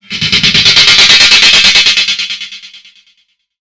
Doubles OSC (Ondes Saw & Metal)
DISTORTION, DELAY A BANDE, REVERBE FEEDBACK, CHORUS VINTAGE, PHASER, FILTRE VINTAGE (en poles)
Mode Monophonique, joué en Arpège.
NOTA: toujours aucun NOISE.
Résultat 02 (100% au Virus TI):
Sur ce nouveau patch, on retrouve l'esprit", l'ambiance, mais d'un autre coté, jon sens qu'Il manque :